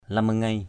/lʌm-ma-ŋeɪ/ (d.) cây so đũa = Sesbania grandi flora.